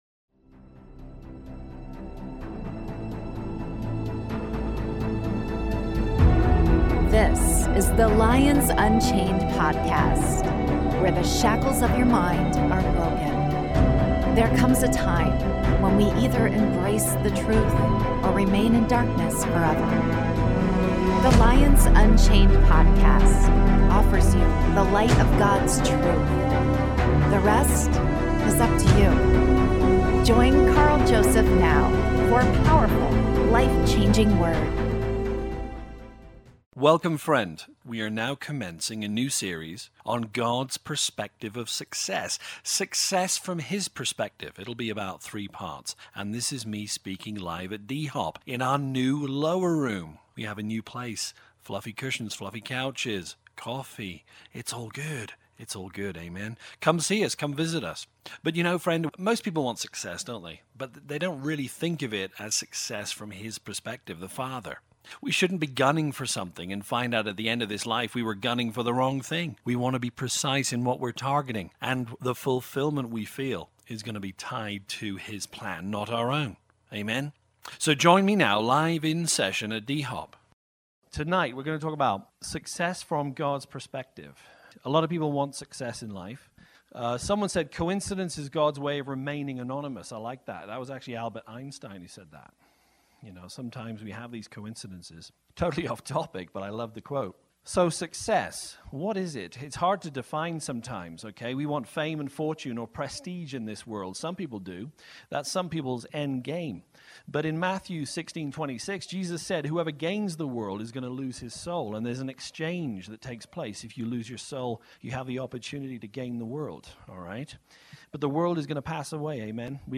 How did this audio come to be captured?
Success from God’s Perspective: Part 1 (LIVE)